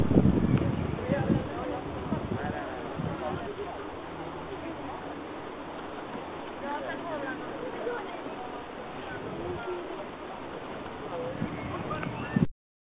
Tävlingen gick av stapeln på Norrköpings Brukshundklubb.
Gnyendet i bakgrunden kommer från Chili, som inte alls ville ligga stilla hos husse när Kenzo och jag var inne på planen...